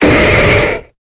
Cri de Mackogneur dans Pokémon Épée et Bouclier.